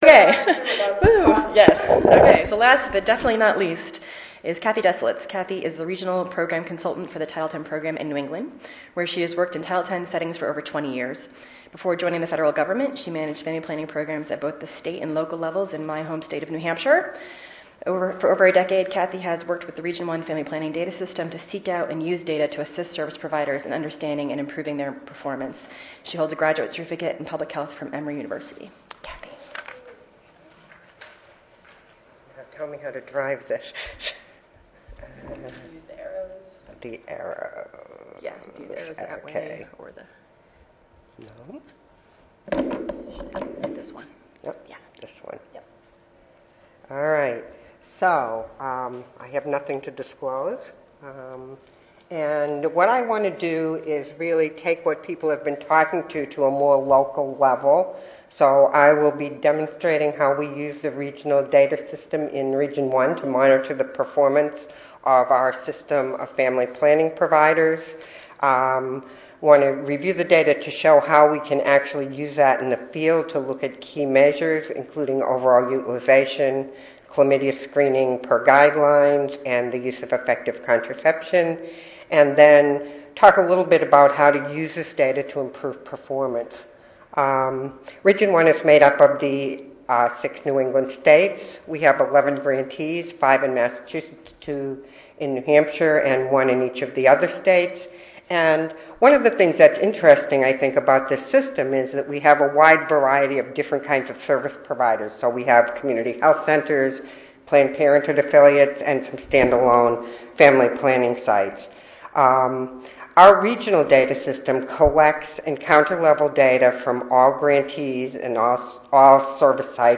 5071.0 The changing landscape in family planning: Standards of care, surveillance, and performance improvement Wednesday, November 6, 2013: 8:30 a.m. - 10:00 a.m. Oral The federal Title X Family Planning Program, established by the Public Health Service Act in 1970, provides family planning and related preventive services to improve the reproductive health of women and men.